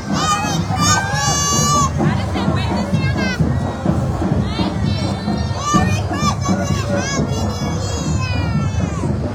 Downtown Emporia was a festive place for the Emporia Area Chamber of Commerce’s 47th annual Christmas Parade on Tuesday.
8580-kid-sound.wav